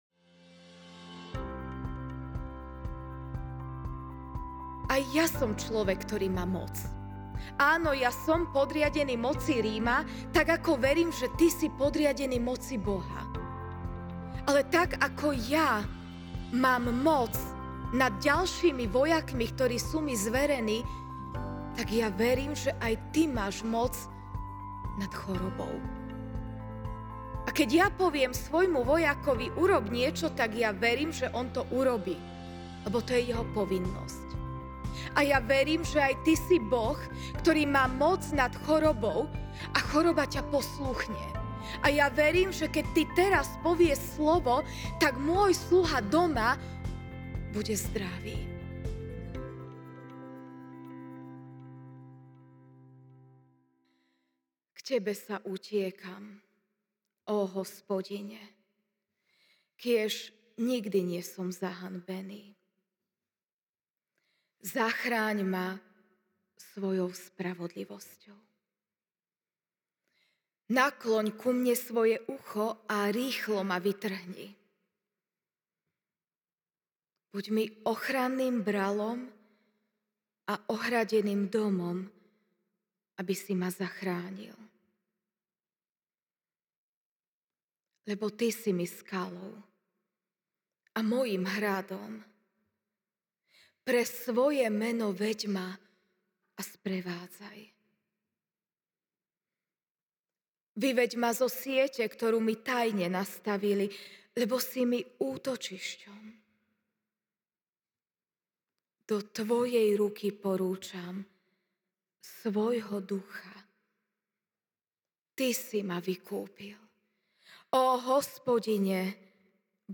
Vitajte na našom podcaste, kde si môžete vypočuť pravidelné nedeľné kázne.